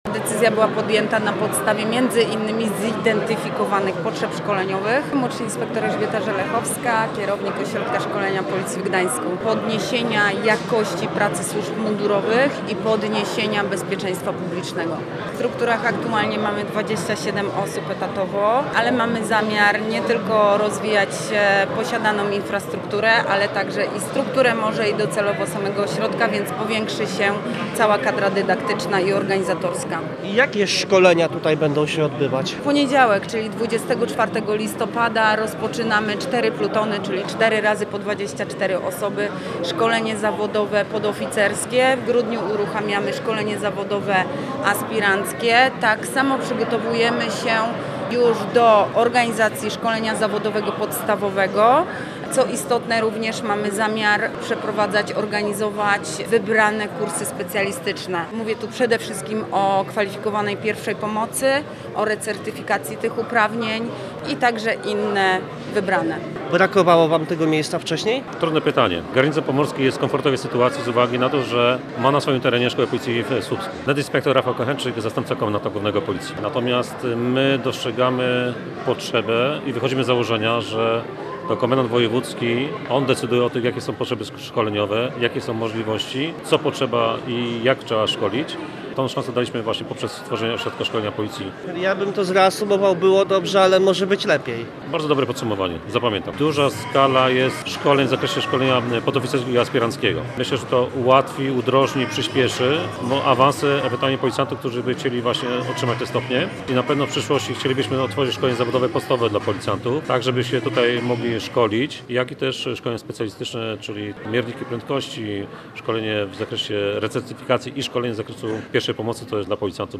Posłuchaj materiały reportera: https